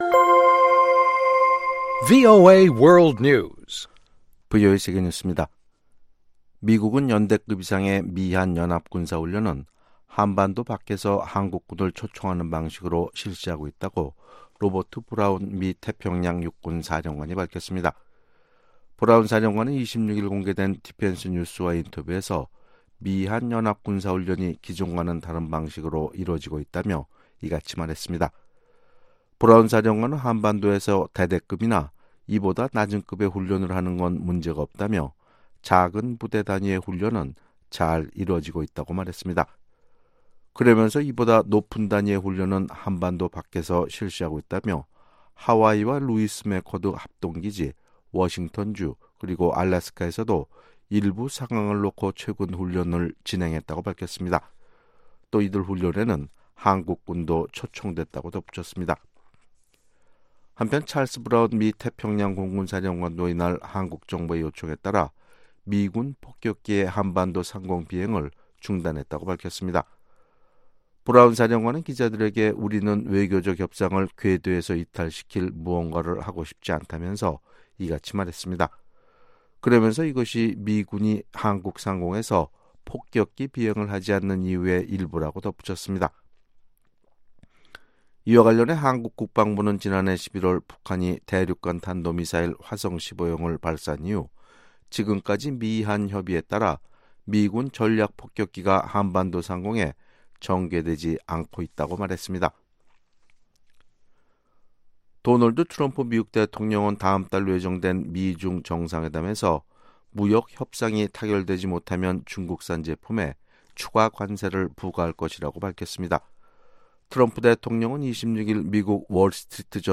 VOA 한국어 아침 뉴스 프로그램 '워싱턴 뉴스 광장' 2018년 11월 28일 방송입니다. 미국의 태평양 군 사령관은 대단위 연합훈련이 한반도 밖에서 실시되고 있다고 밝혔습니다. 유엔 안보리 대북제재위원회가 남북 철도연결 공동조사에 대한 한국 정부의 제재 면제 요청을 허가했다고 확인했습니다.